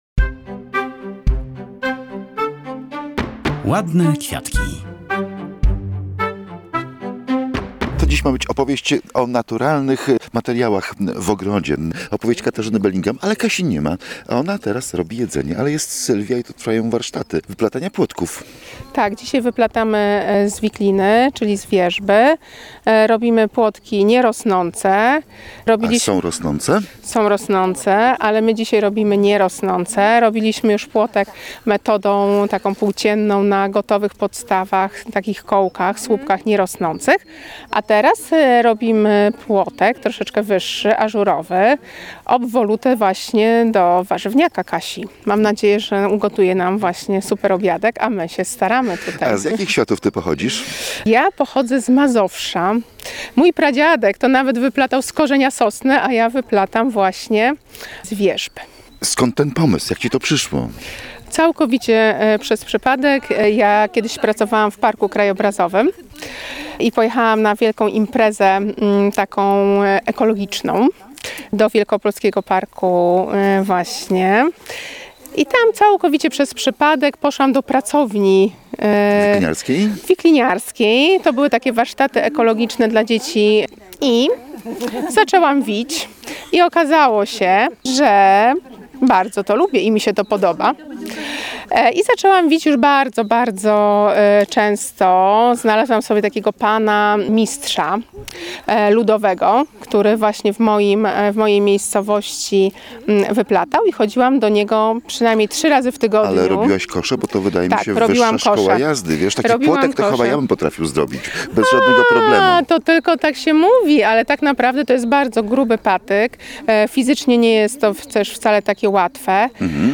Posłuchaj rozmów prowadzonych podczas warsztatów „Wiklina w ogrodzie”: